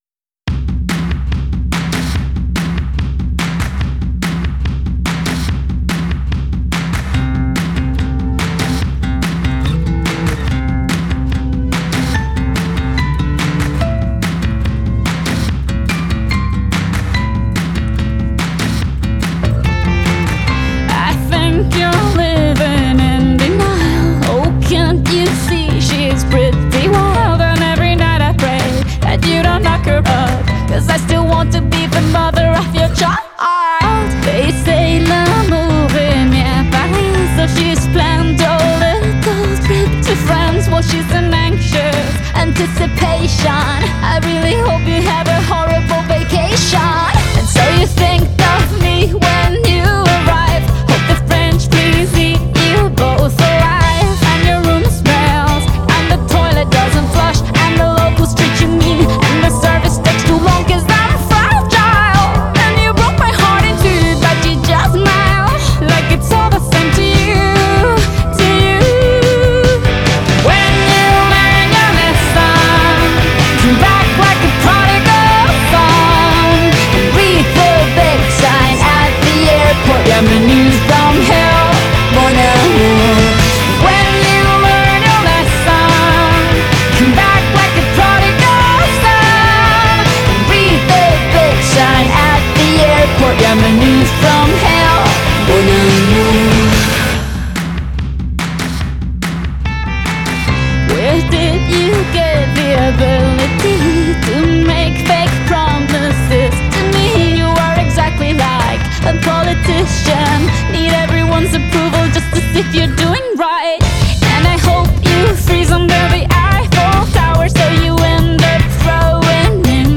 Стиль: Pop